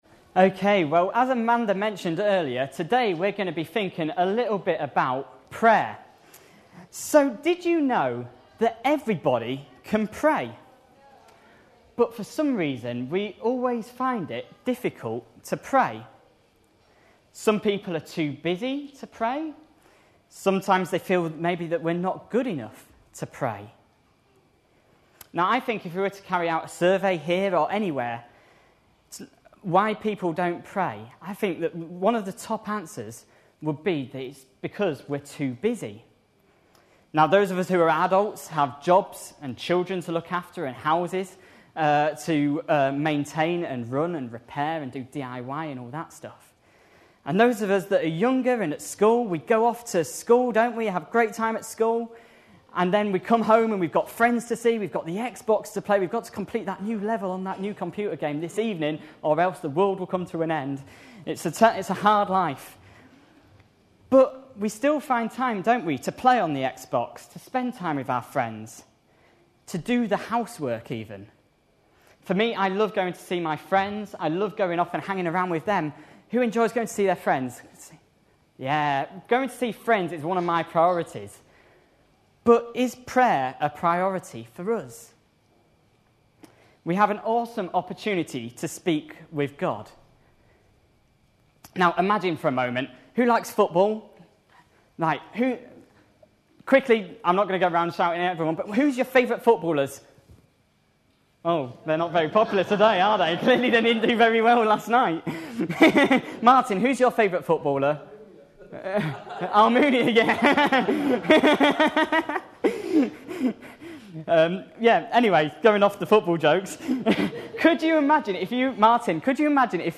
A sermon preached on 20th March, 2011.